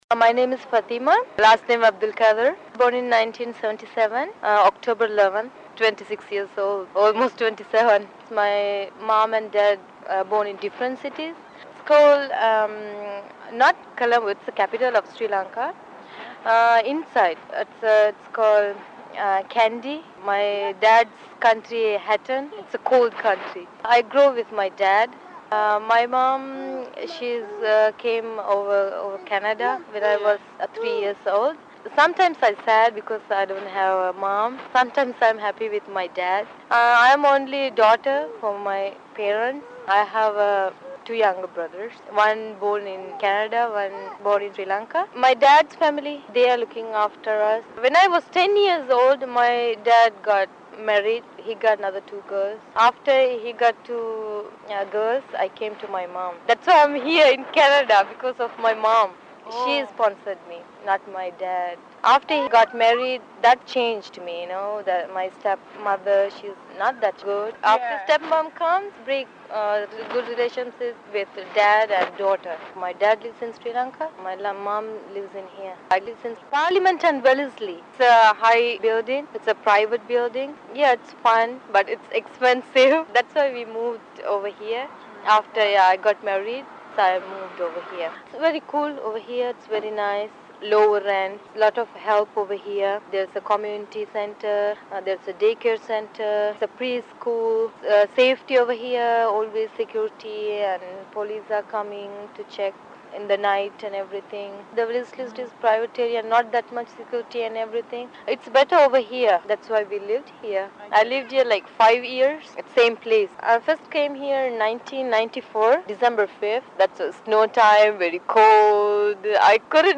Journey Home is a story-telling project that explores the journey from homeland to Canada, through the voices of Regent Park residents.